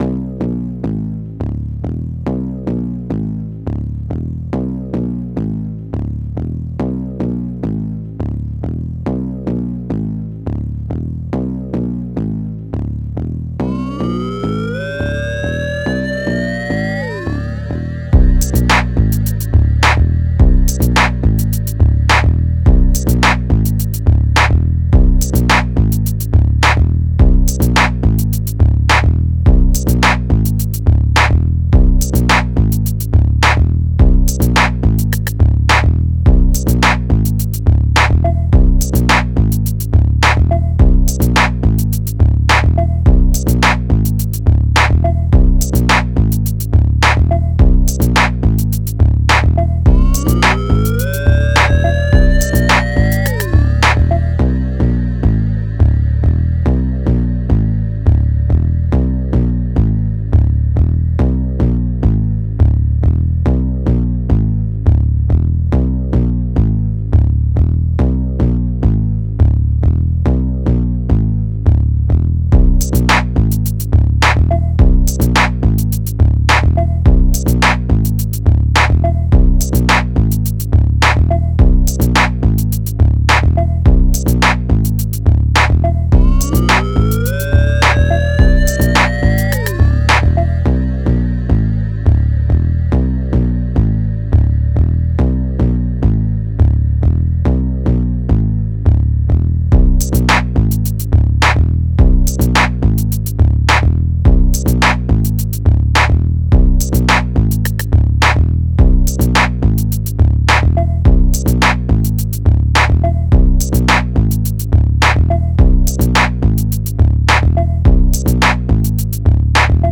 From 40 Hz to 120 Hz (centering on 80 Hz)